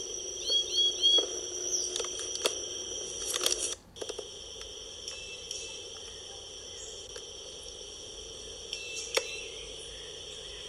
Chara Verde (Cyanocorax yncas)
lo que no se es si los que se oyen de primero tambien son cyanocorax? me podrian ayudar, en el foro no pude montar el audio
Nombre en inglés: Green Jay
Localidad o área protegida: Ibague, Zona de Boquerón
Condición: Silvestre
Certeza: Vocalización Grabada